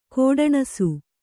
♪ kōḍaṇasu